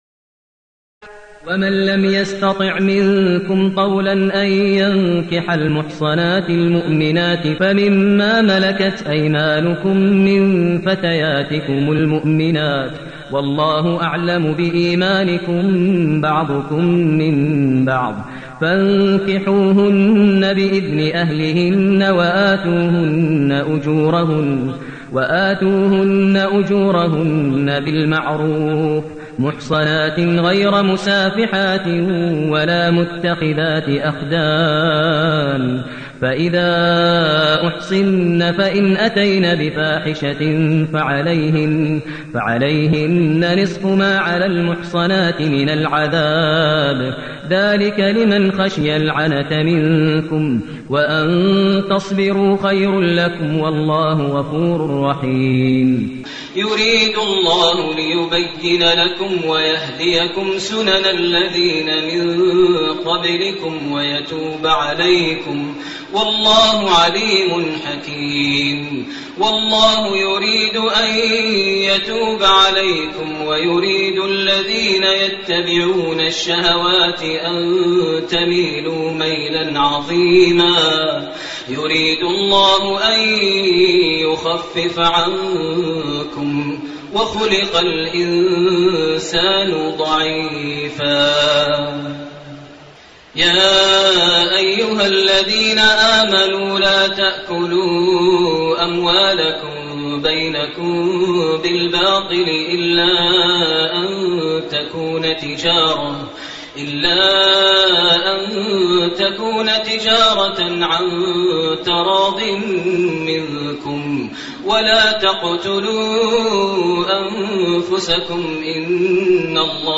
تراويح الليلة الخامسة رمضان 1429هـ من سورة النساء (25-87) Taraweeh 5 st night Ramadan 1429H from Surah An-Nisaa > تراويح الحرم المكي عام 1429 🕋 > التراويح - تلاوات الحرمين